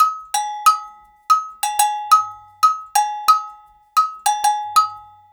90 AGOGO02.wav